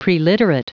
Prononciation du mot preliterate en anglais (fichier audio)
Prononciation du mot : preliterate